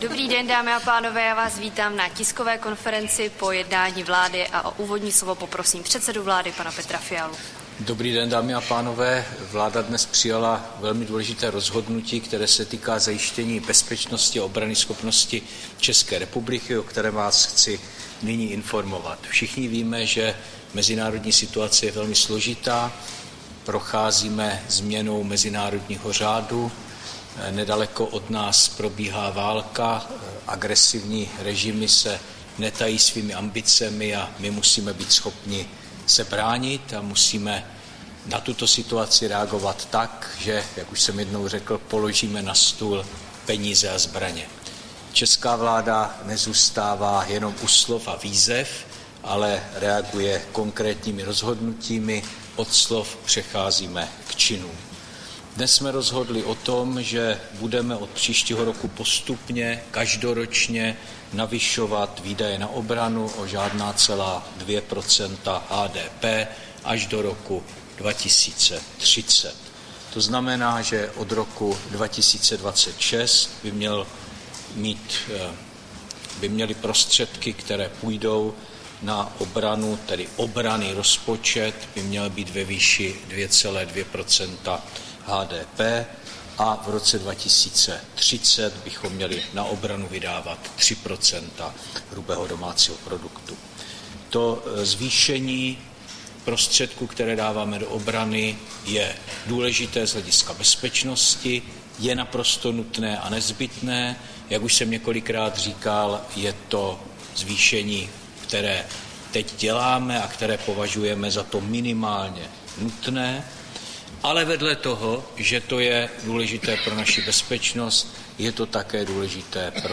Tisková konference po jednání vlády, 5. března 2025